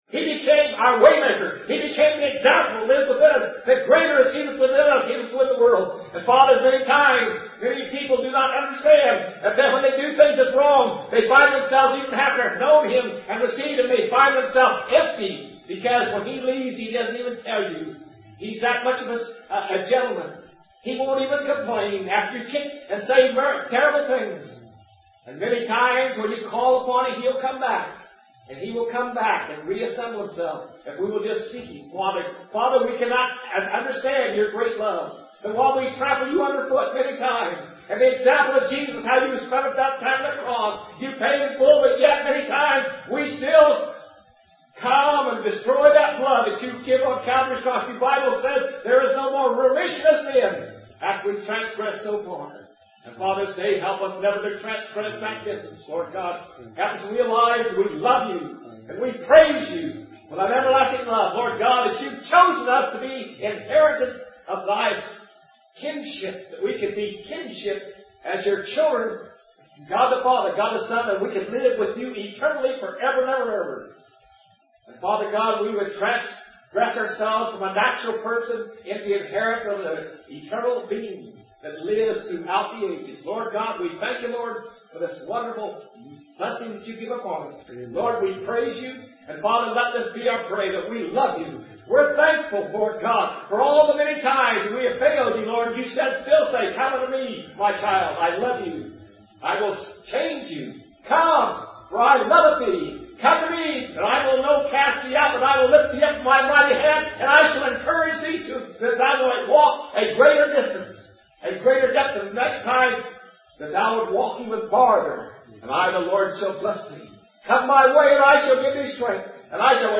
Listen to Korea Miracle (super message rescued from bad cassette)